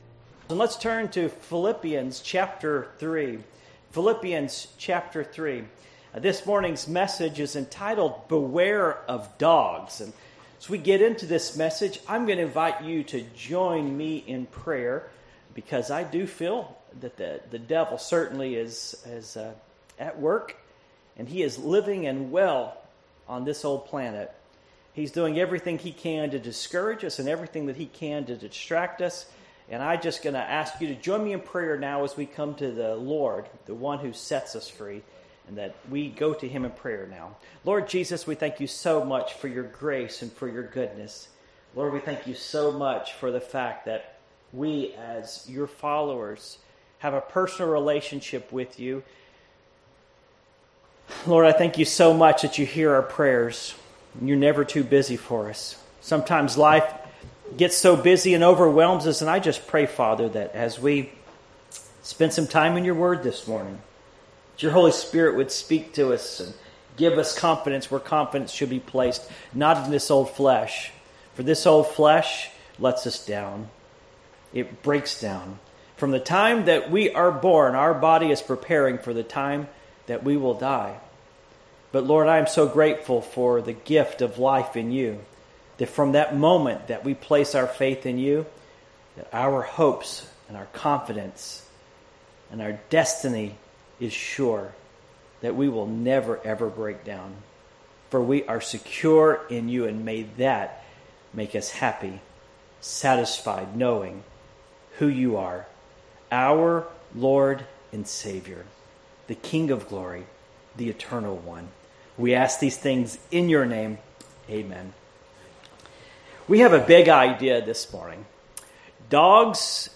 The Ministry of the Encourager Passage: Philippians 3:1-7 Service Type: Morning Worship Philippians 3:1-7 1 Finally